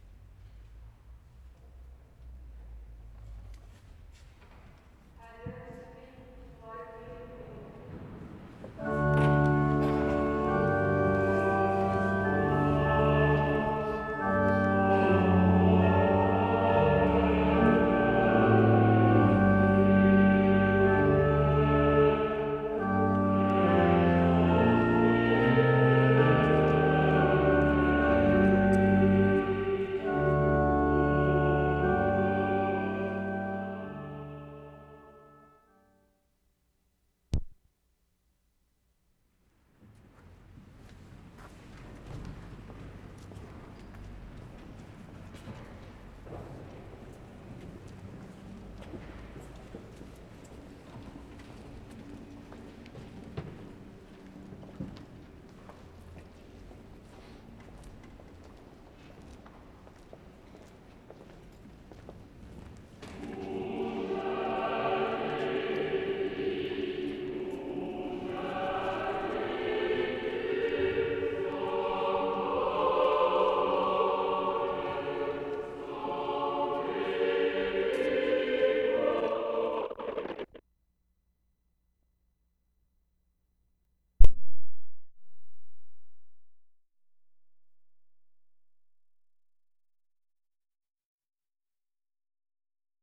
WORLD SOUNDSCAPE PROJECT TAPE LIBRARY
Stockholm, Sweden Feb. 16/75
INSIDE STORKYRKAN, END OF SERVICE (not marked on field recording sheet).